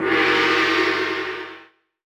gong.wav